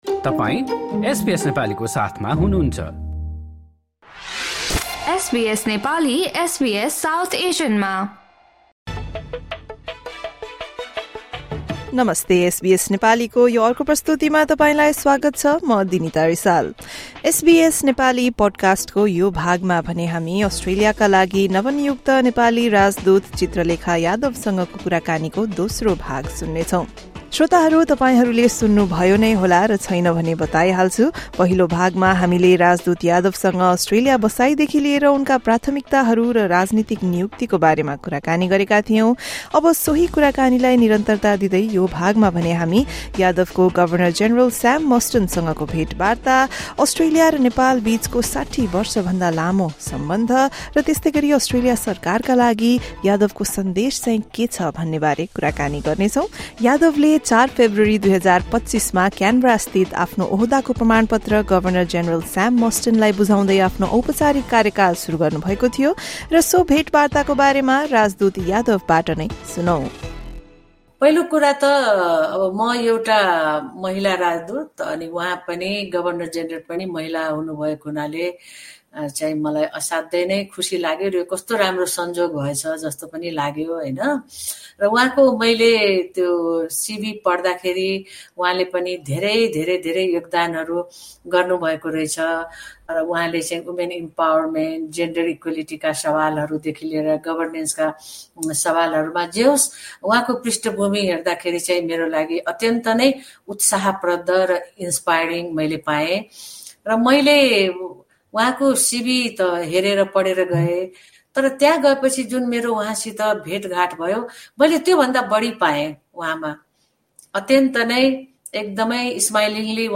नेपाली एम्बेसीको निर्माण र दुई देशबिच सिधा हवाई सम्पर्क जस्ता विषयहरूमा भएका चर्चा र थप विकास सहायताका लागि अस्ट्रेलिया सरकारलाई आफ्नो सन्देशबारे यादवले एसबीएस नेपालीसँग गरेको कुराकानीको यो दोस्रो भाग सुन्नुहोस्।